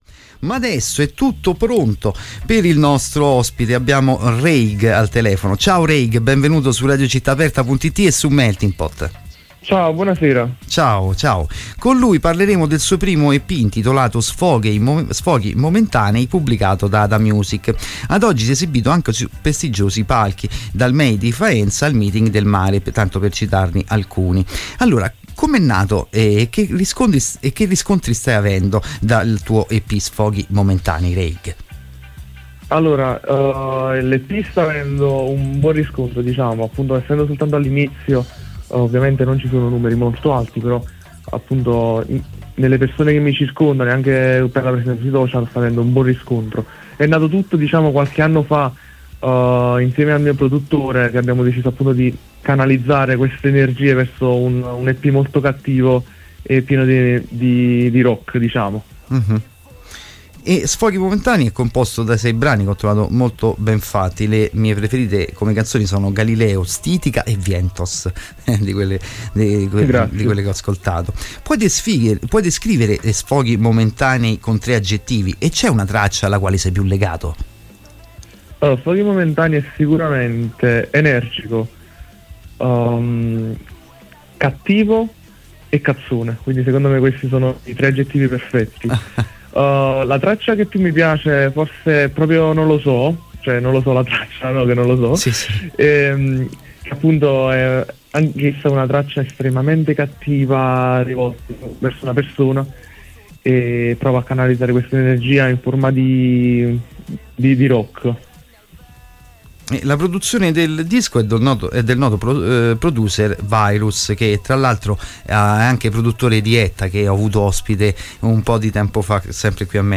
L’Ep è un concentrato di pura adrenalina rock/punk, un sound potente che ti prende a schiaffi e ti lascia steso.